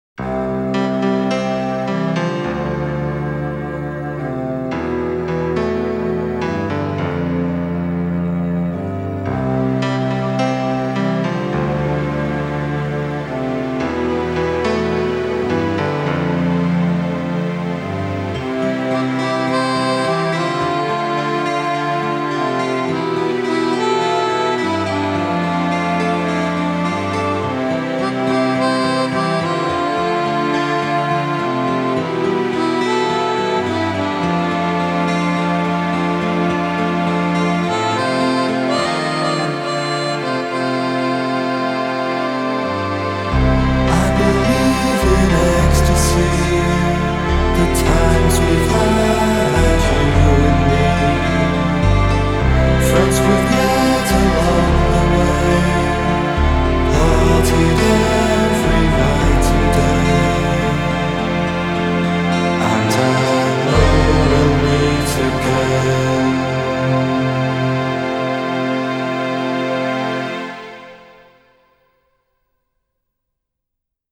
Жанр: Electronic, Pop, House, Synth pop